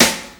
MAIS SNARE.wav